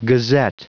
Prononciation du mot gazette en anglais (fichier audio)
Prononciation du mot : gazette